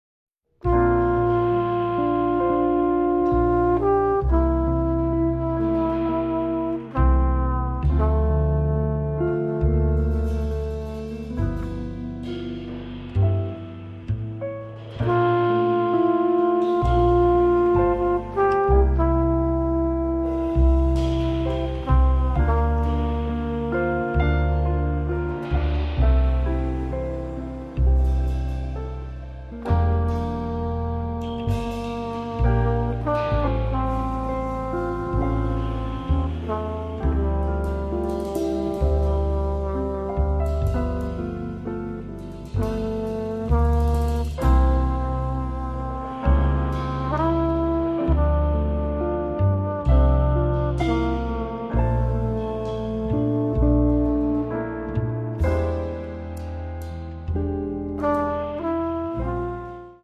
tromba e filicorno
chitarra
contrabbasso